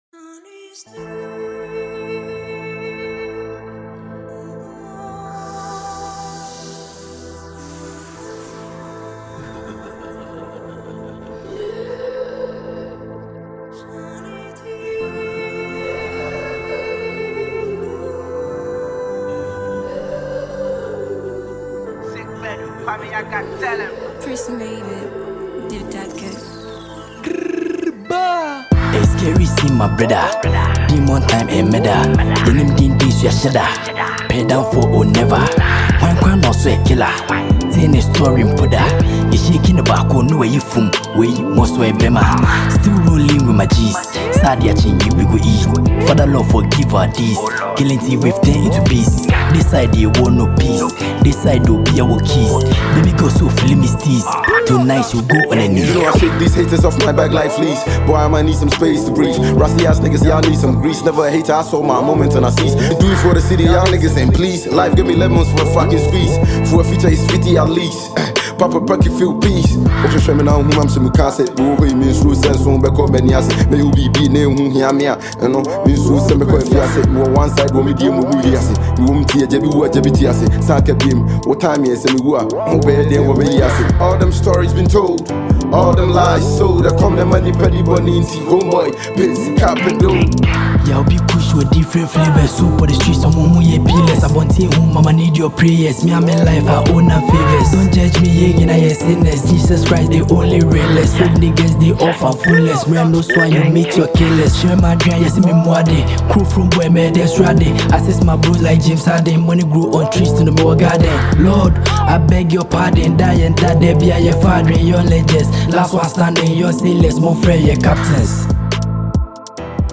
a Ghanaian asakaa musician